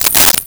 Coins Shake In Hand 02
Coins Shake in Hand 02.wav